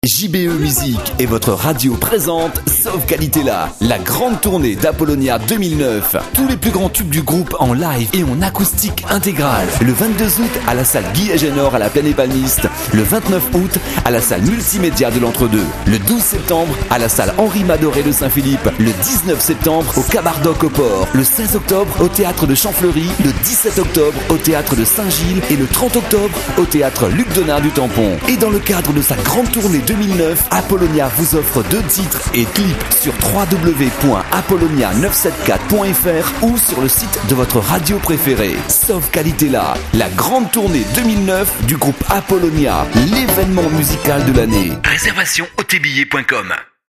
Les Spots Pub Radio
le spot de la tournée